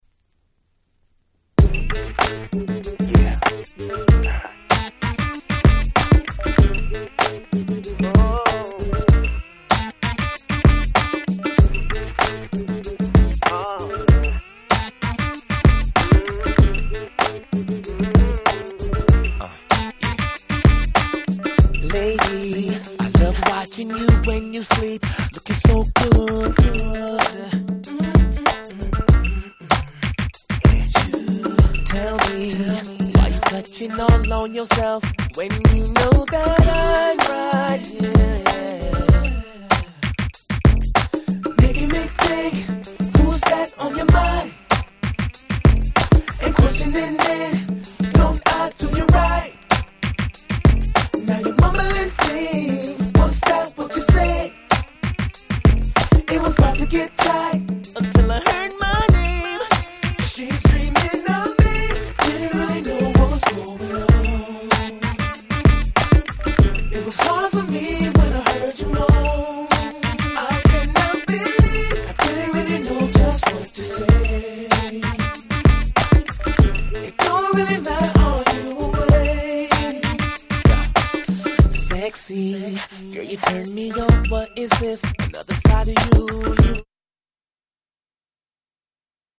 Nice Indie Soul !!